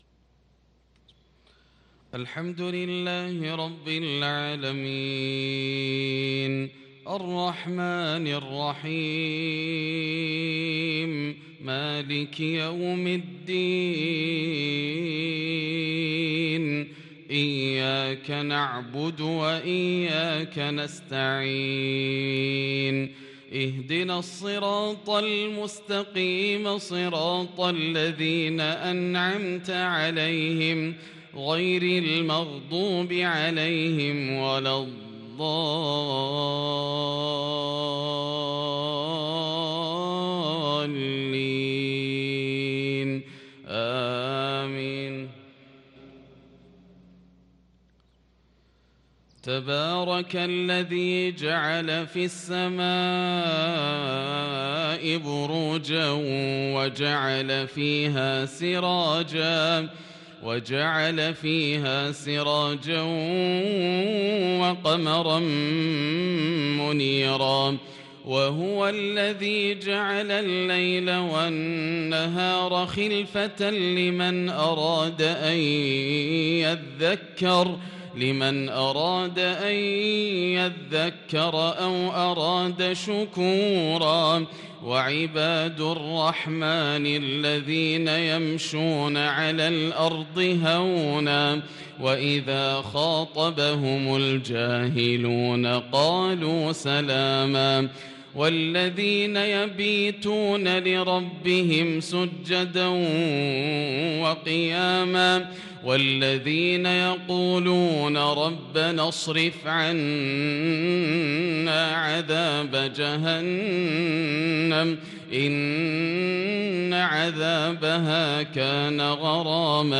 صلاة العشاء للقارئ ياسر الدوسري 3 ربيع الآخر 1443 هـ
تِلَاوَات الْحَرَمَيْن .